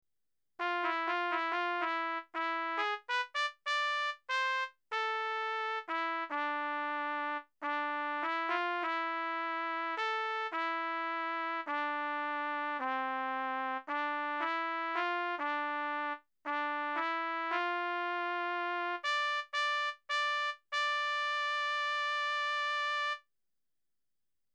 Bugle Pieces only.